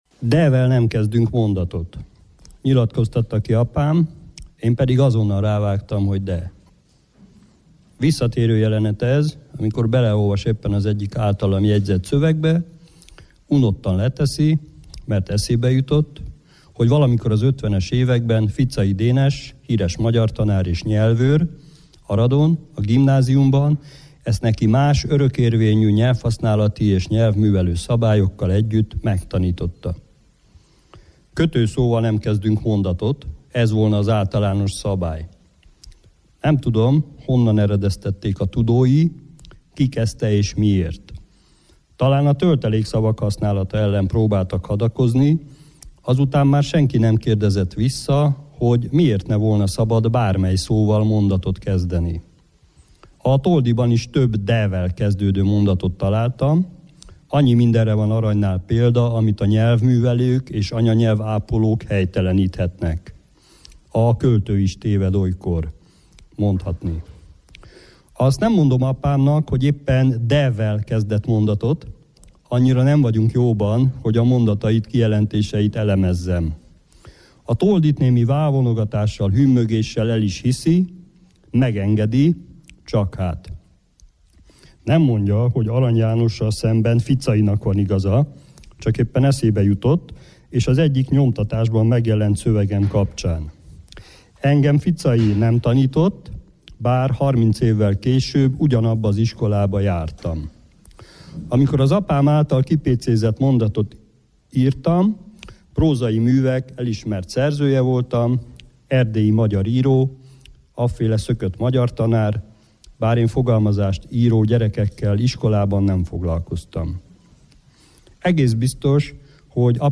* A marosvásárhelyi könyvbemutatónak rendkívül beszédes hely, tér jutott az András Lóránt mozgásTársulat székhelyén, az egykori zsinagógában, egy betonszürke hangulatú romteremben.